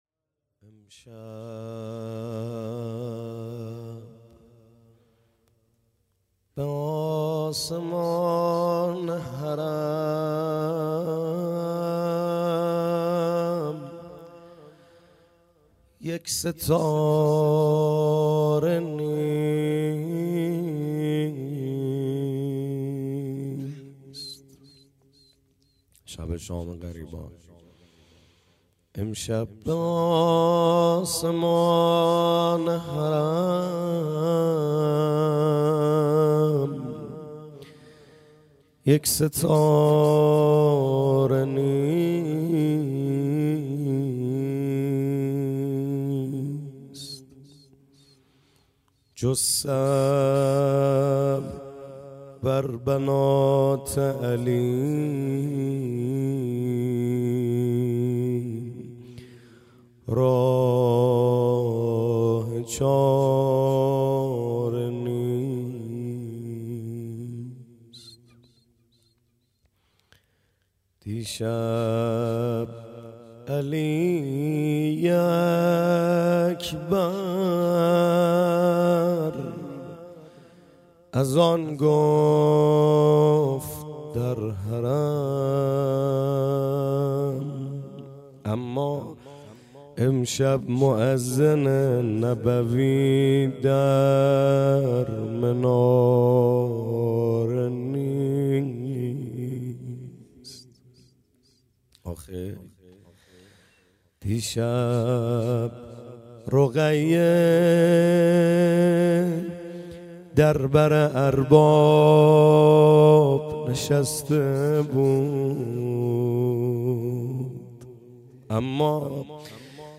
روضه
محرم 98 - شب یازدهم